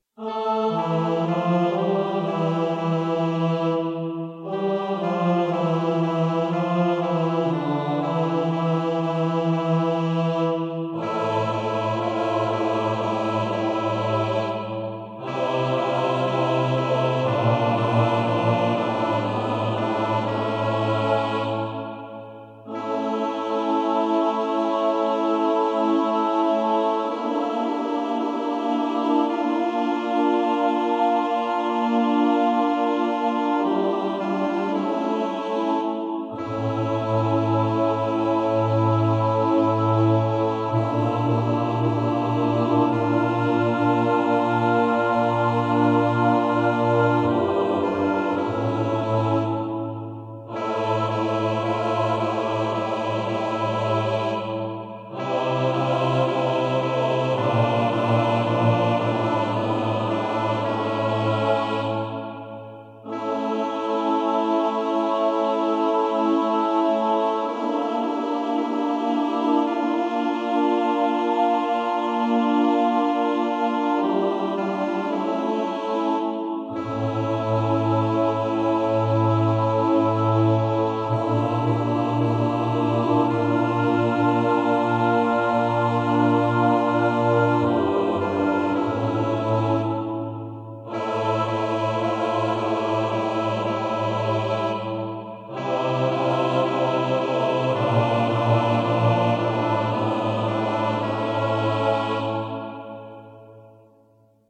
SATB
pro smíšený sbor